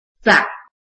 臺灣客語拼音學習網-客語聽讀拼-大埔腔-入聲韻